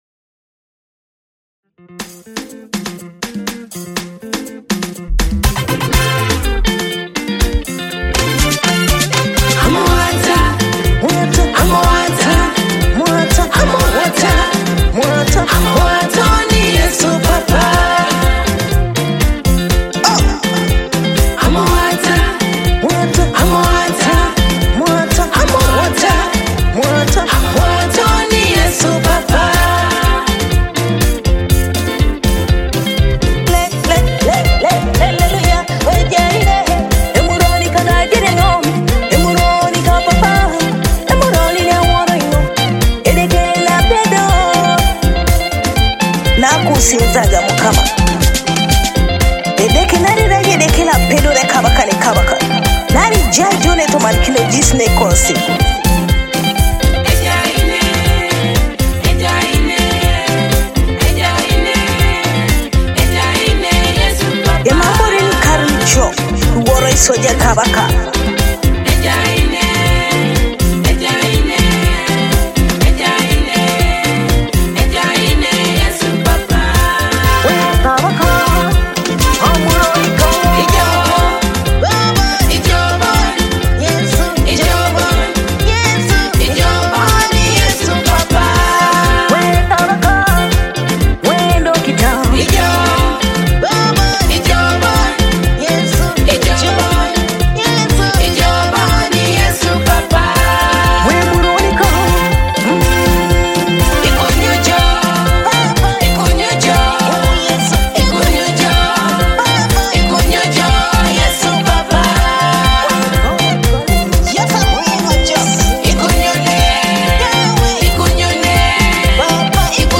Teso gospel Praise song